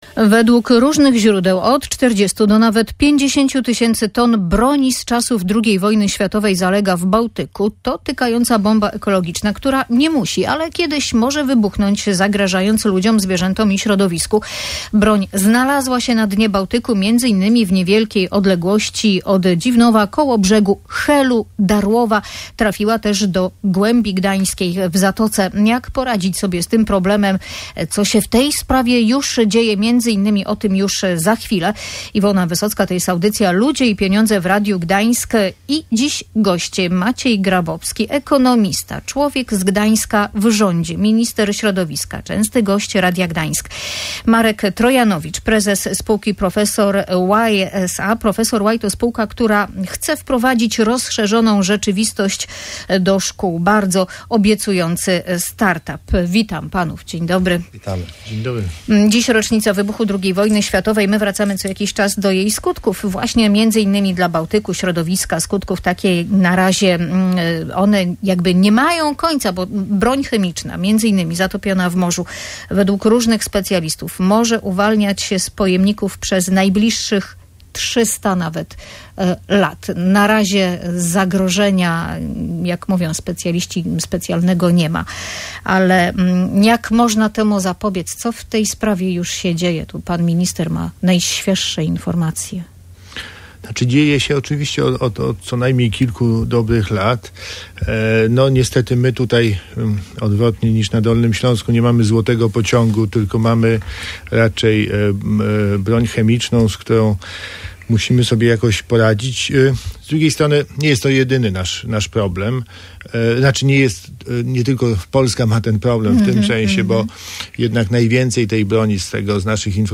Będziemy szukali metod unieszkodliwienia broni chemicznej, mówił minister środowiska Maciej Grabowski w audycji Ludzie i Pieniądze.